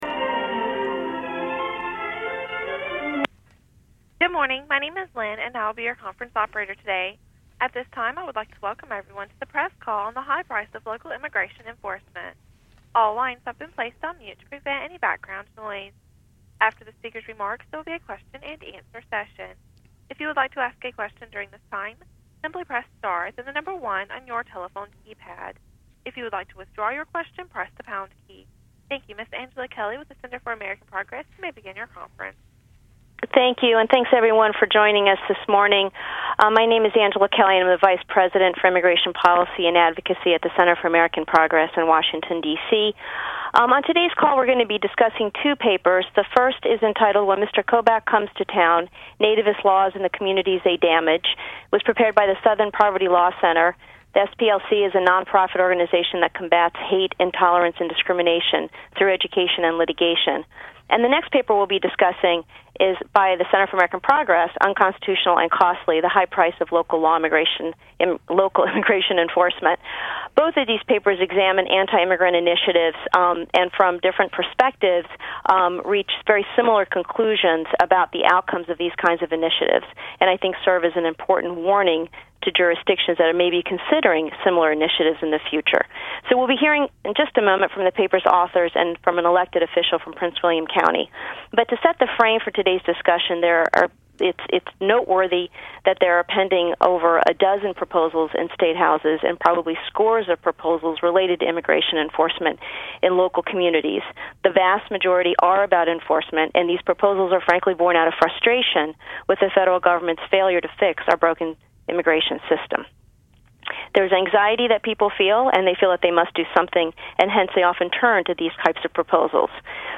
Listen to today’s press call here. (mp3)